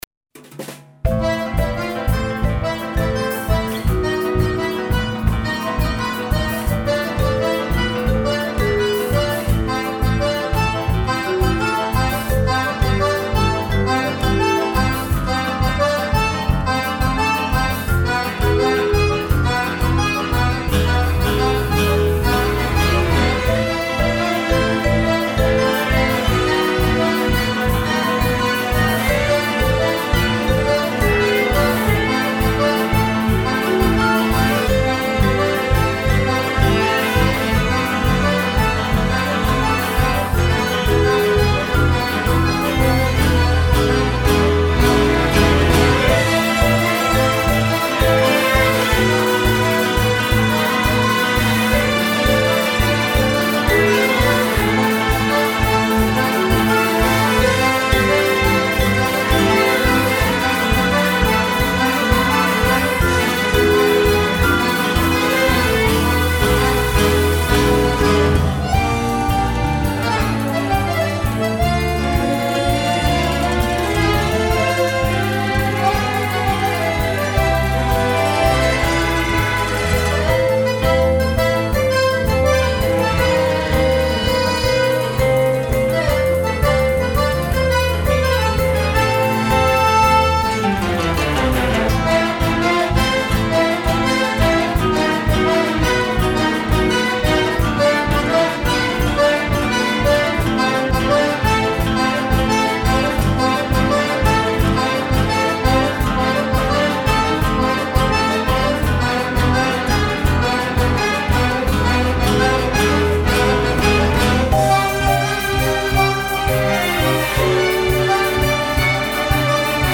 plettri, archi, percussioni, accordeon